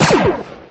Stormtrooper Blast